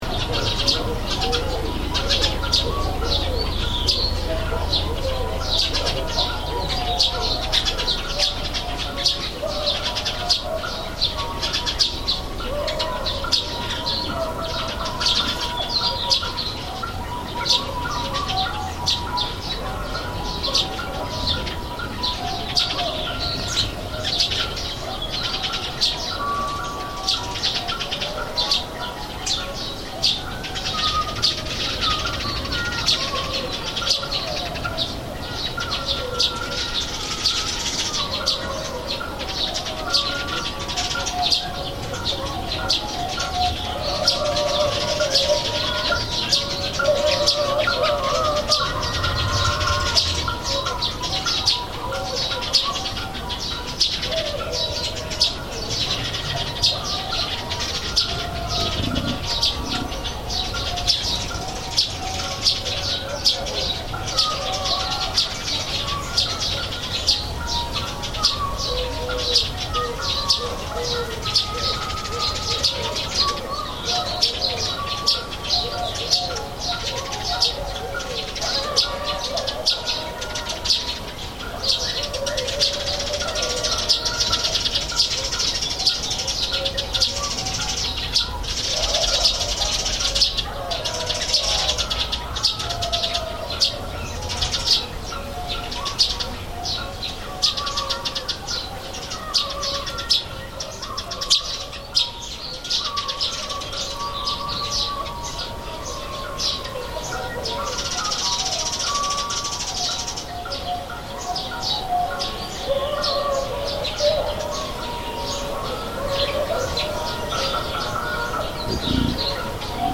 Village, QinQiang opera and birds
A quiet and nice village besides Yellow River at Lanzhou City. QinQiang opera from the loud speaker in the village and this is very local sound. Happy birds are busy on working. What a wonderful soundscape.